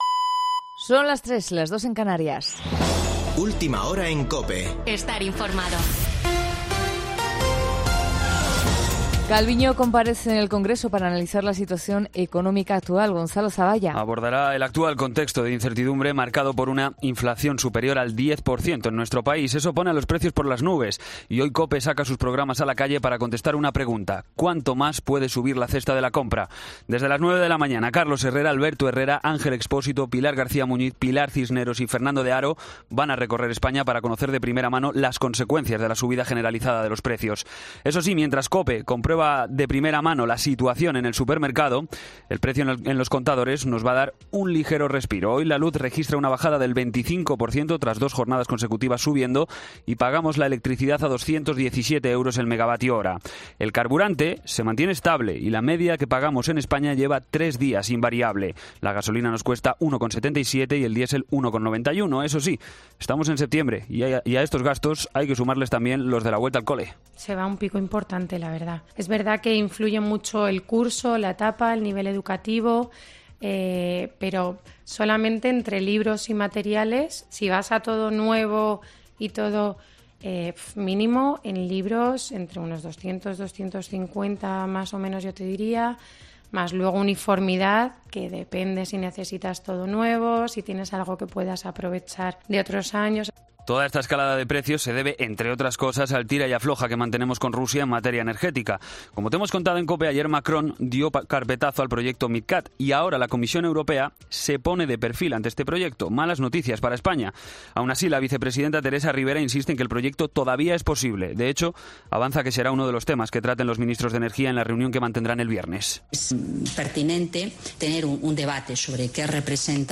Boletín de noticias de COPE del 7 de septiembre de 2022 a las 03:00 horas